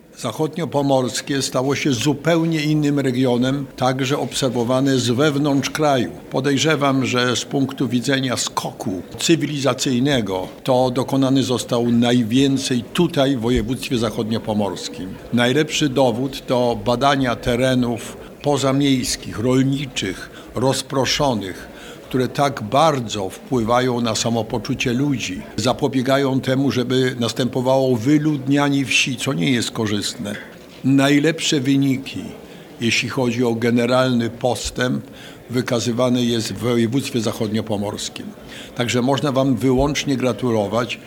O przemianach, jakie dokonały się w Zachodniopomorskiem dzięki środkom unijnym, mówił gość specjalny dzisiejszej konferencji – były premier, europarlamentarzysta Jerzy Buzek